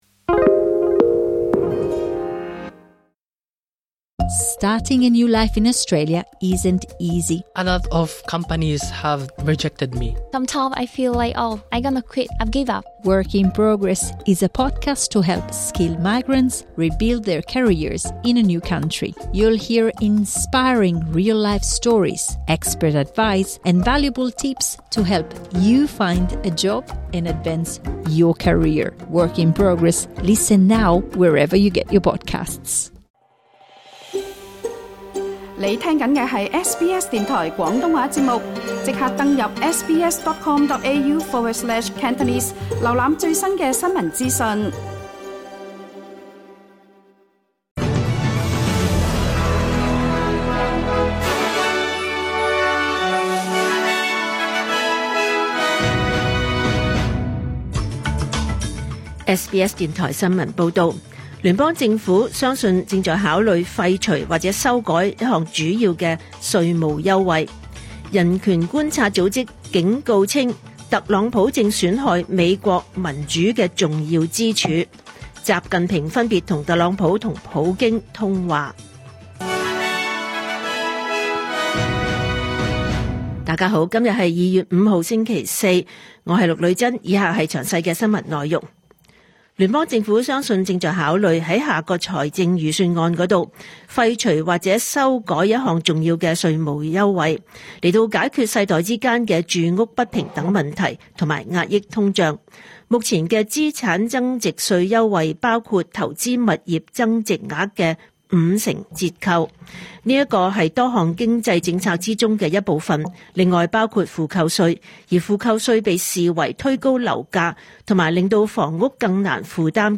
2026 年 2 月 5 日 SBS 廣東話節目詳盡早晨新聞報道。